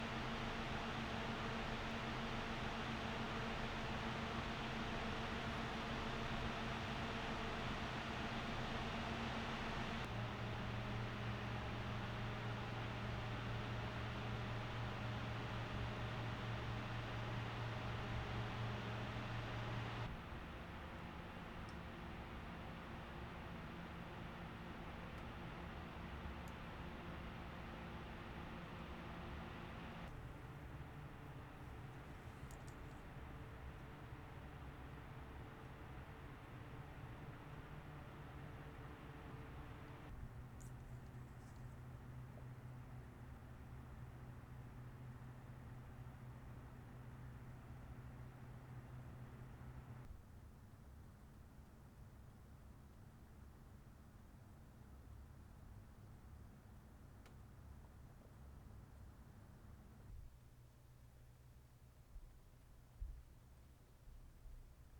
Aufnahme weiterer Kühler (anderes Verfahren, anderes Mikrofon)
Noctua NH-D15 G2: Lautstärke von 100 bis 45 Prozent PWM (31 dB)